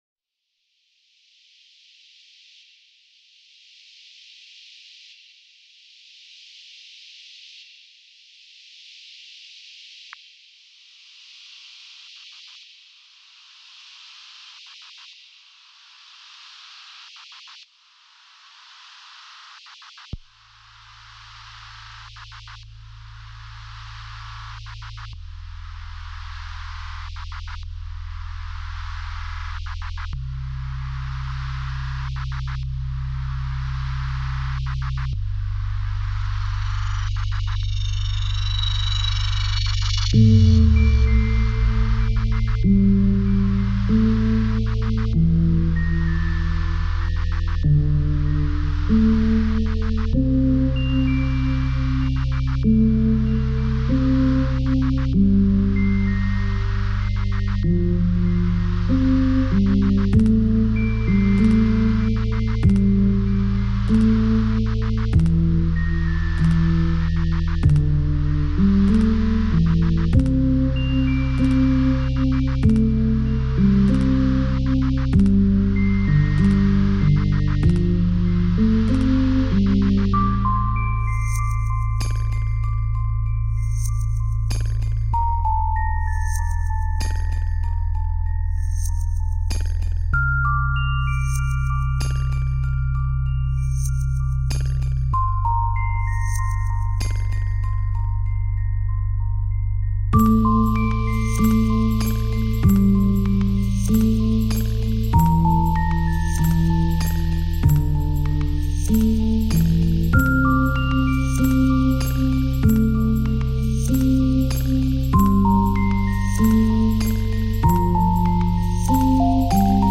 glitch ambient